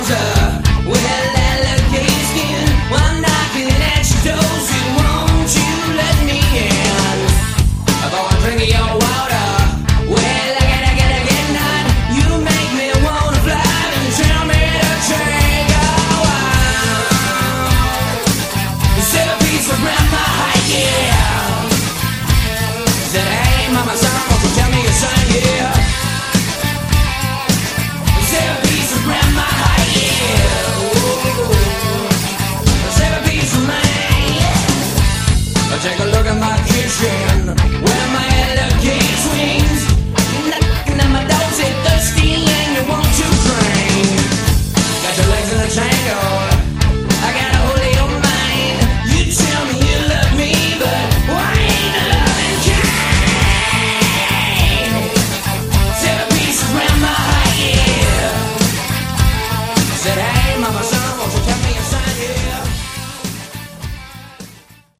Category: Hard Rock
lead vocals, harmonica
guitar, backing vocals
bass, backing vocals
drums, backing vocals